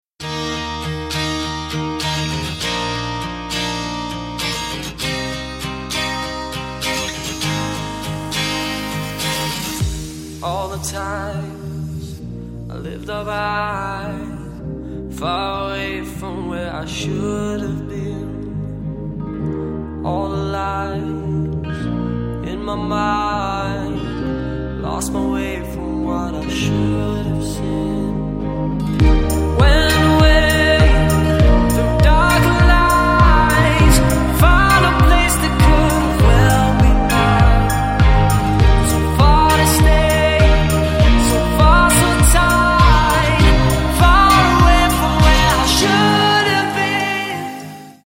• Качество: 160, Stereo
мужской вокал
deep house
Chill
vocal